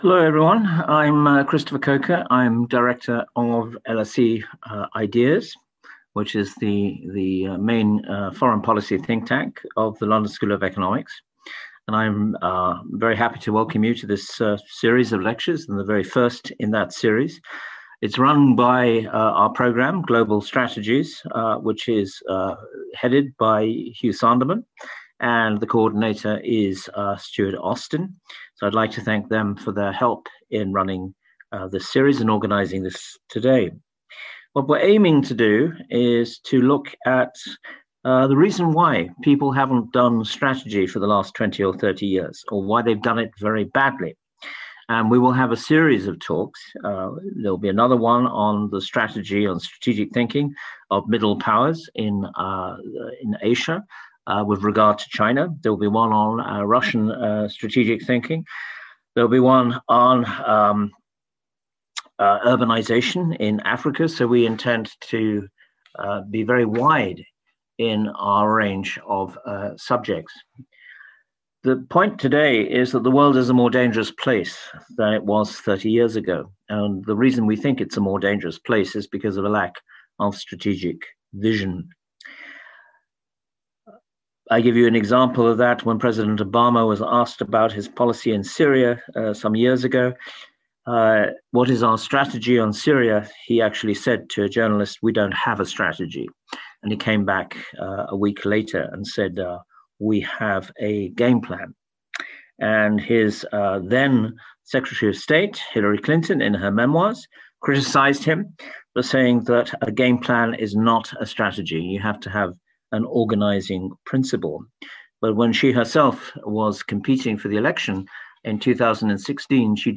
Meet the speaker and chair